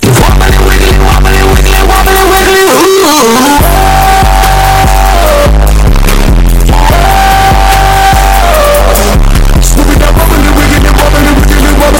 Very Loud Prank Sound Effect Download: Instant Soundboard Button